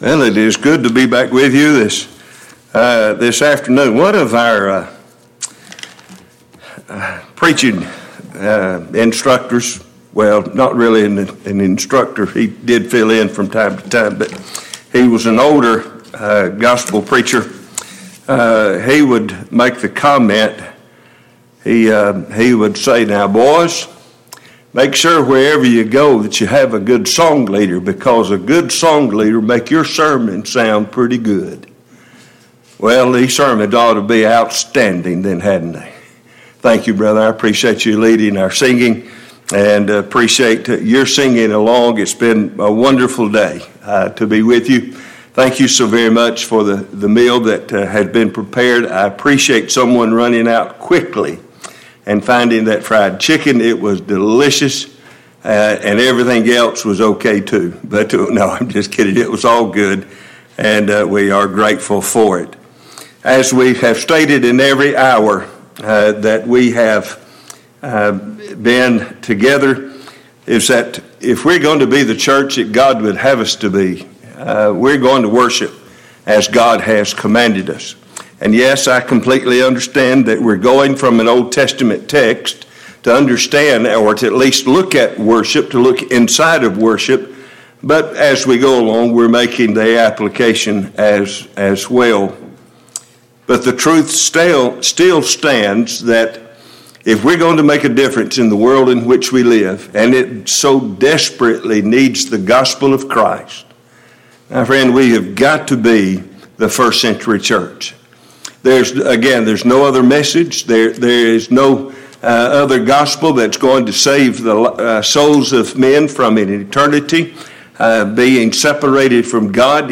Passage: Psalms 57 Service Type: Gospel Meeting